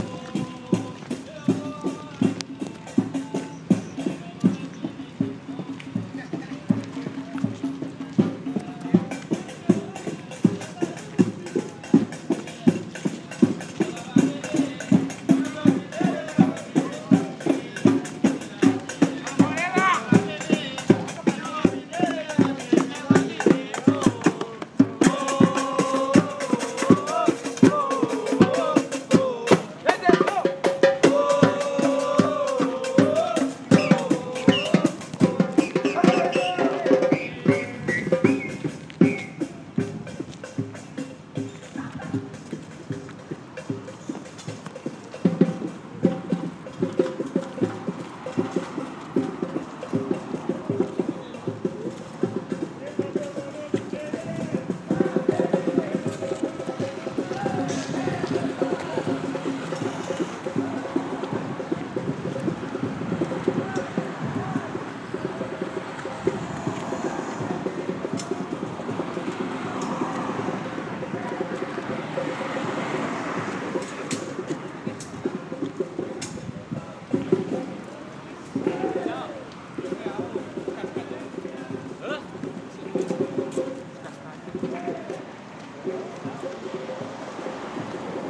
Batucada no parque